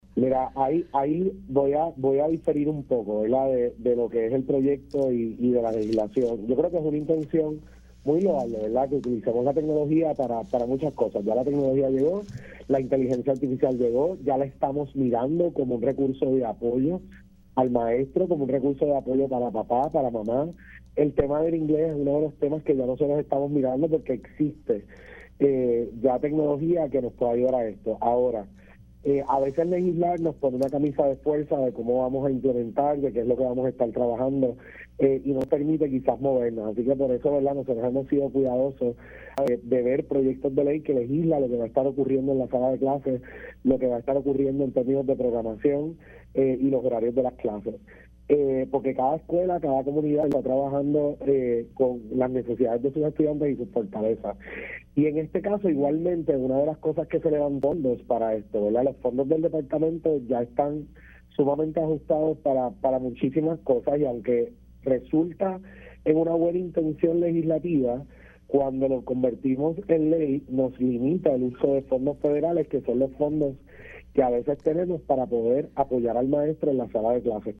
El secretario de Educación, Eliezer Ramos indicó en Pega’os en la Mañana que difiere del Proyecto de la Cámara 427, que busca implementar la inteligencia artificial para la enseñanza de inglés en el Departamento de Educación junto a la Oficina de Innovación y Servicios de Tecnología (PRITS).